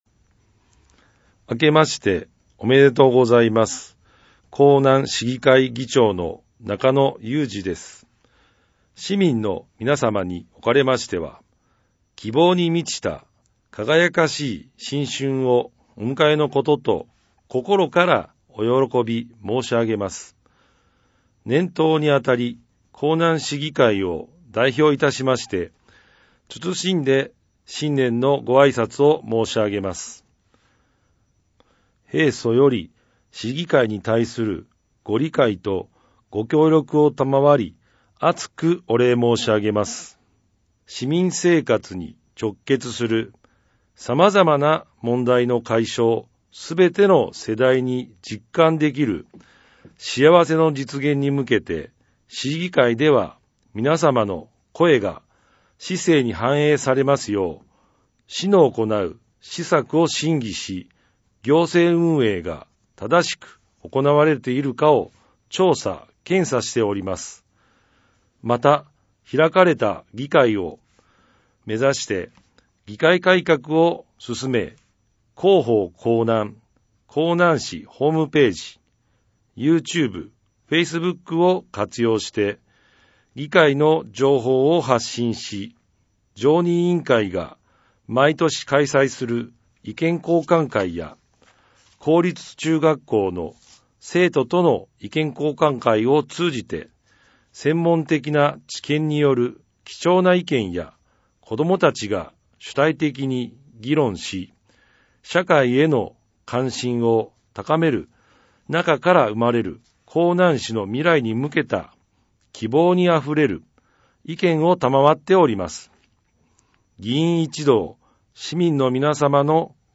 江南市議会議長 中野 裕二 議長新年あいさつ音源ダウンロード （mp3 623.0KB） ※録音に際しては、声のボランティア 「やまびこ」の方々にご協力いただきました。